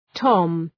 Προφορά
{tɒm}